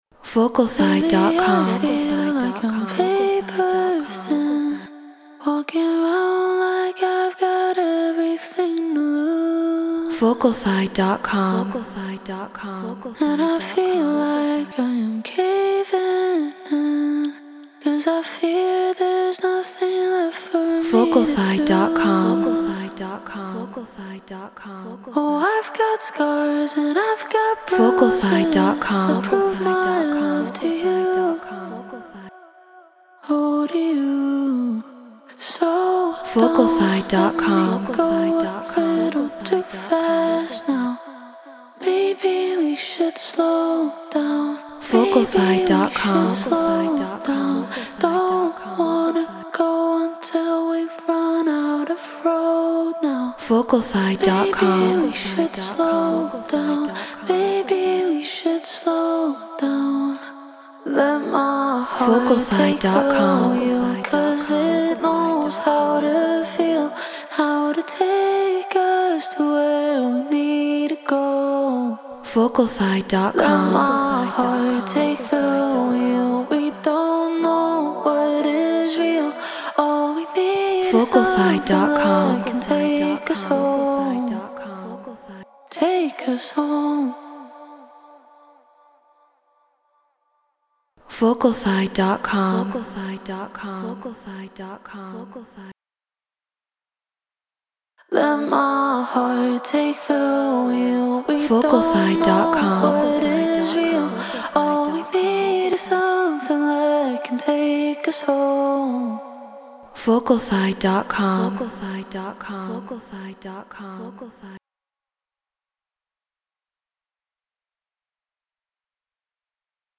Future Bass 155 BPM Bmin
Shure SM7B Apollo Solo Logic Pro Treated Room